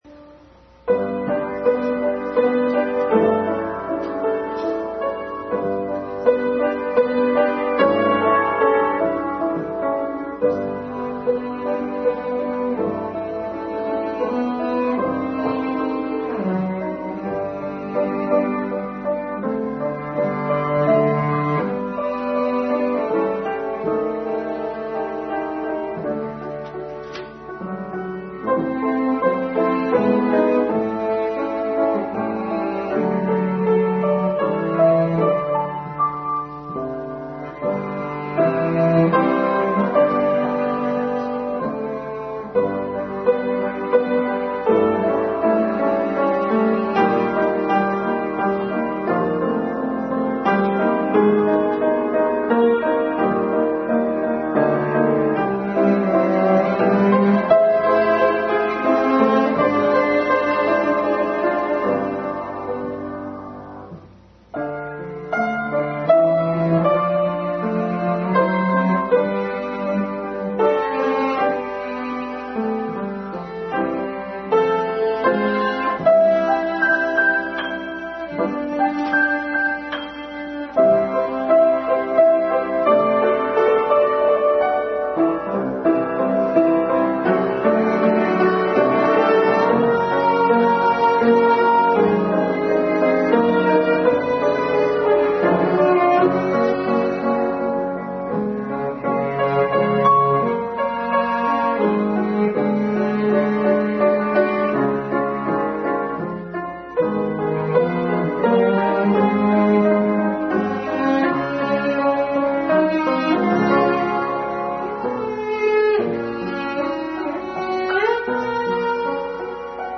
Family Bible Hour message preceded by special music.
Keep The Faith Passage: Jude 1-25 Service Type: Family Bible Hour Family Bible Hour message preceded by special music.